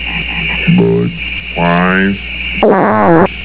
Listen to the Bud frogs as they advertise their drink.